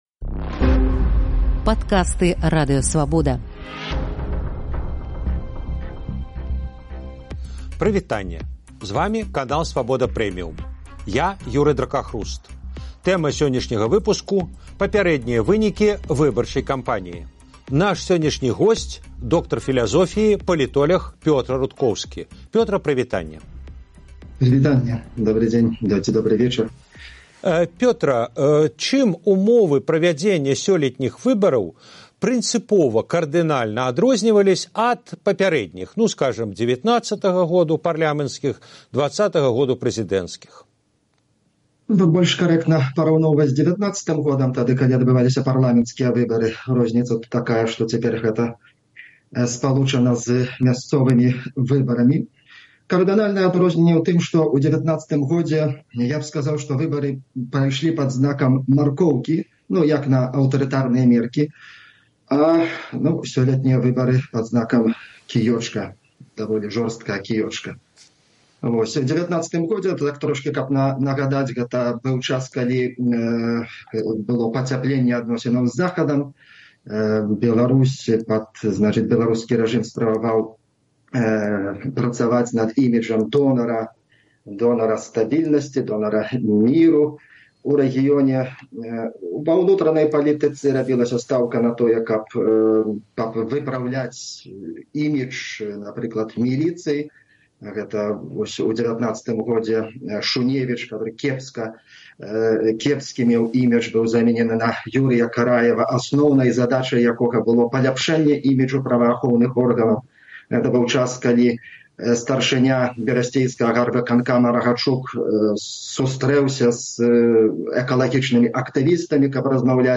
Акцыя BelPol і заявы Лукашэнкі, — палітоляг камэнтуе выбарчую кампанію